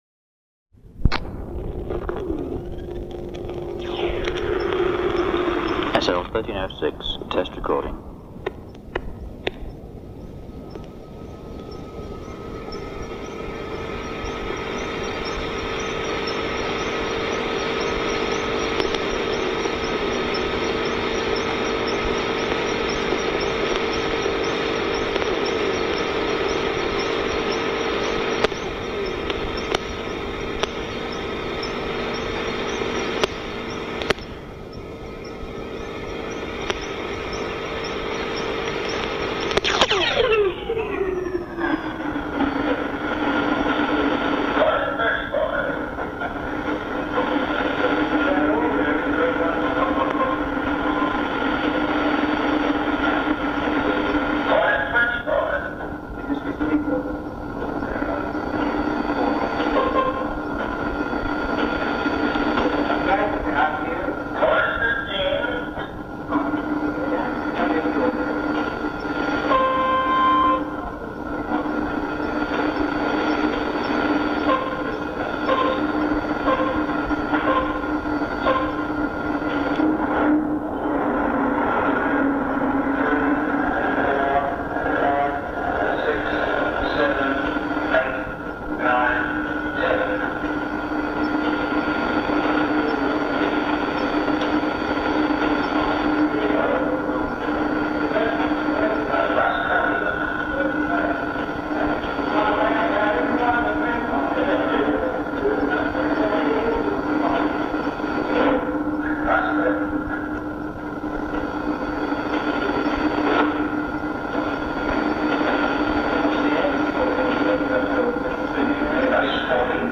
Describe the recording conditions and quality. Unique sound recording of SL-1306 launch